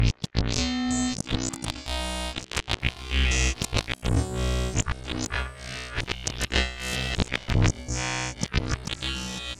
• Crazy Sequenced Texture Synth 100 bpm.wav
Crazy_Sequenced_Texture_Synth_100_bpm__IRT.wav